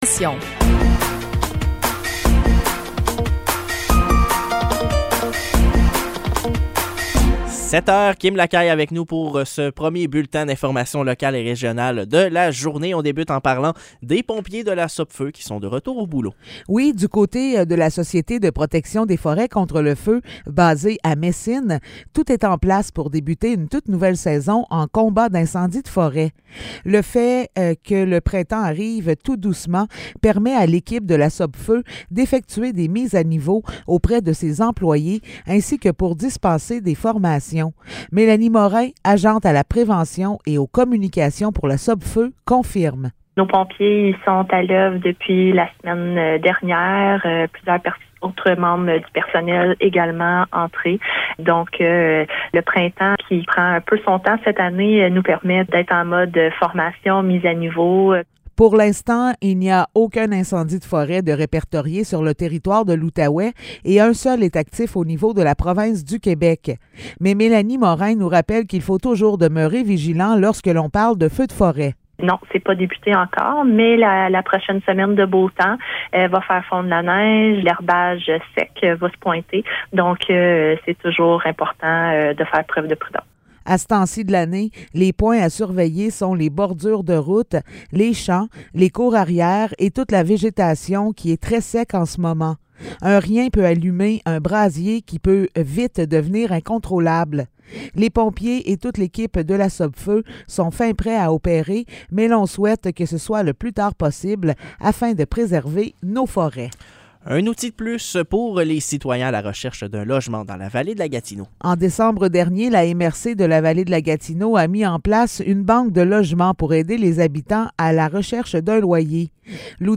Nouvelles locales - 12 avril 2023 - 7 h